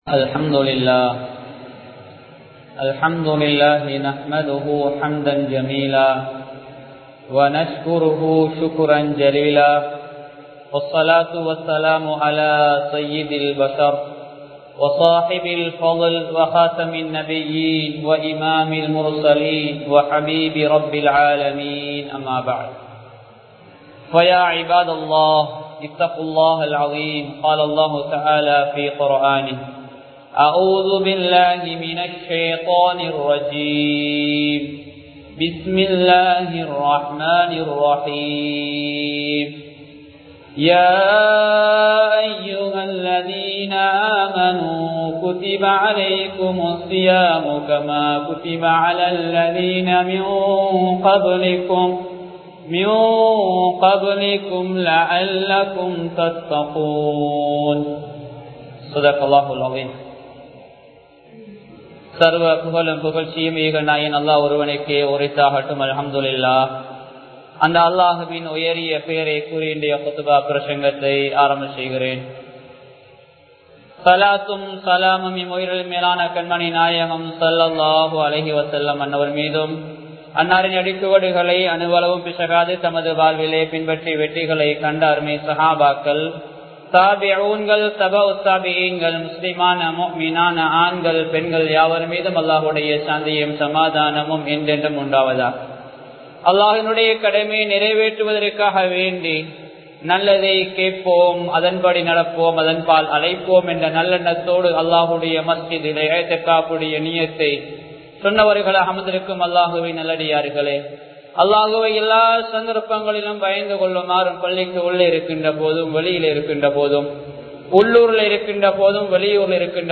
சிறந்த ரமழான் | Audio Bayans | All Ceylon Muslim Youth Community | Addalaichenai